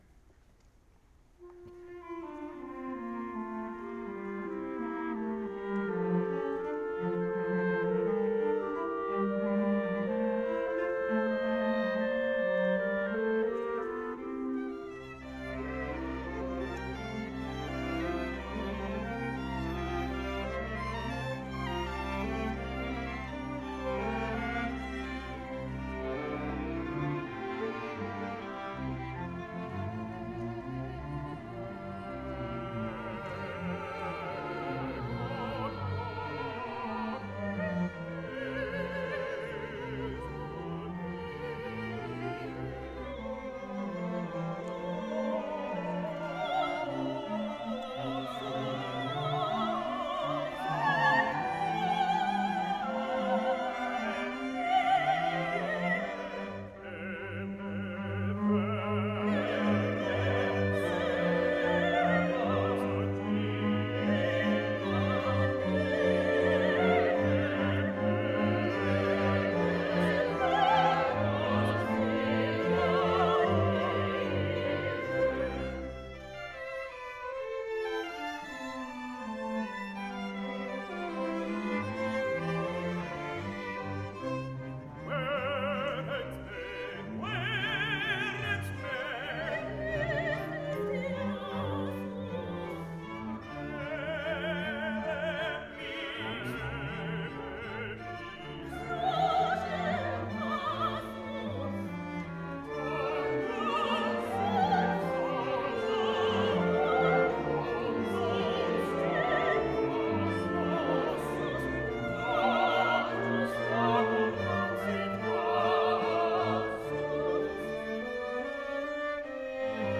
April 21, 2013 Concert
St. Louis Choir and St. Louis Concert Orchestra